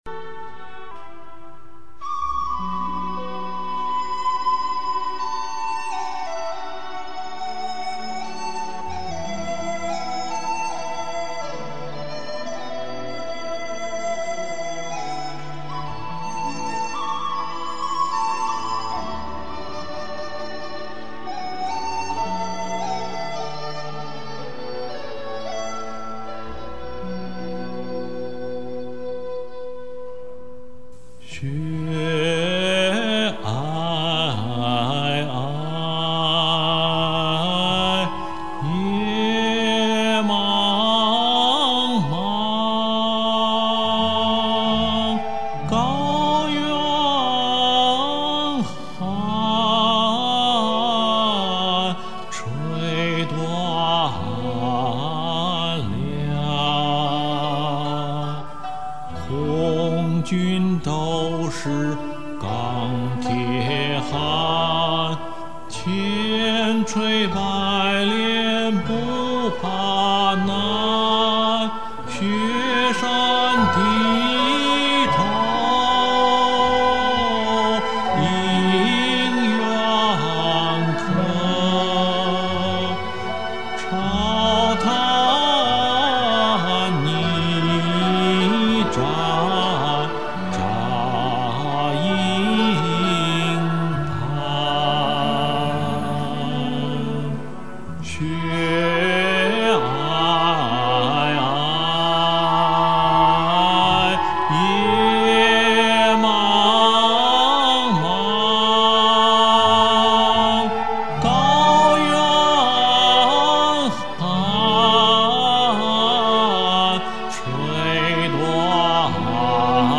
唱的不好，总比没有强啊，呵呵。